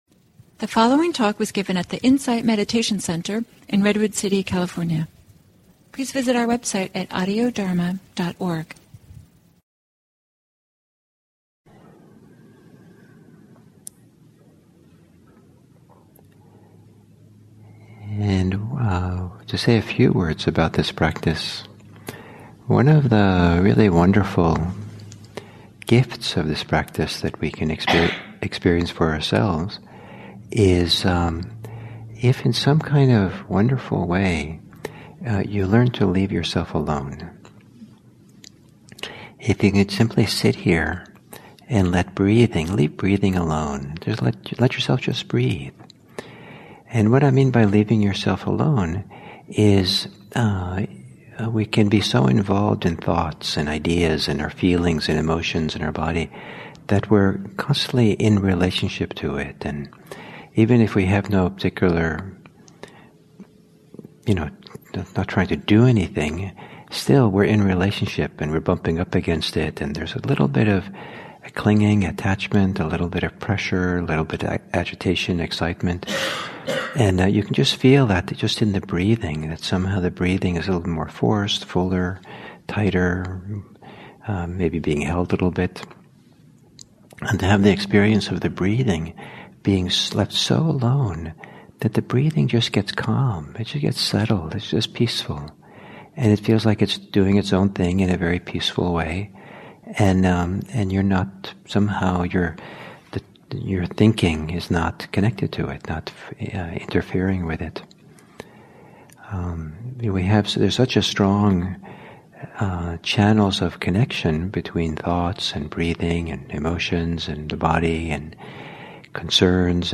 at the Insight Meditation Center in Redwood City, CA.